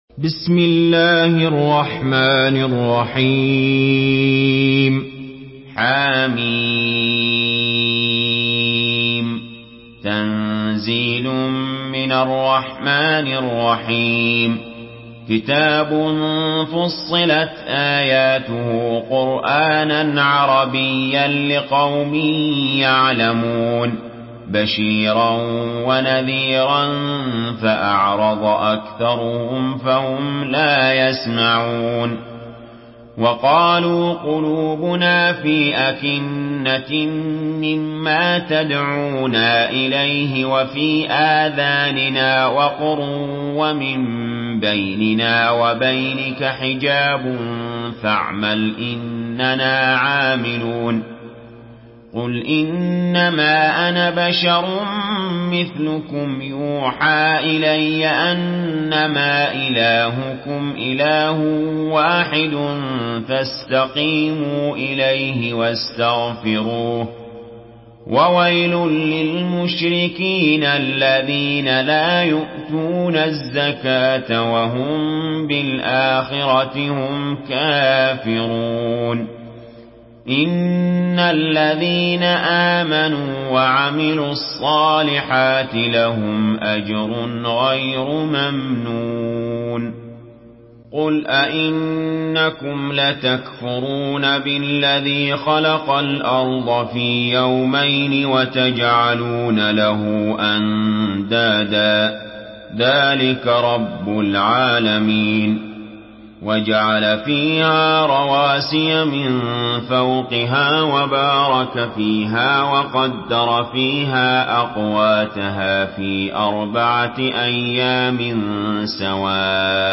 Surah ফুসসিলাত MP3 by Ali Jaber in Hafs An Asim narration.